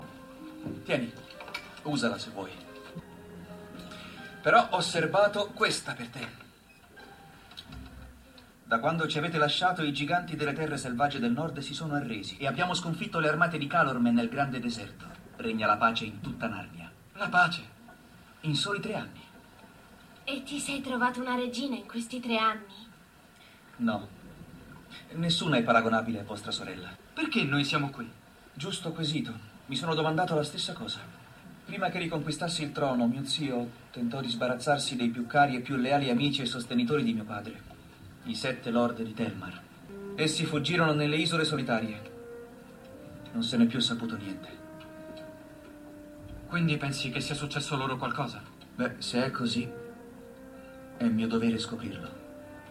Il mondo dei doppiatori